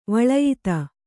♪ vaḷayita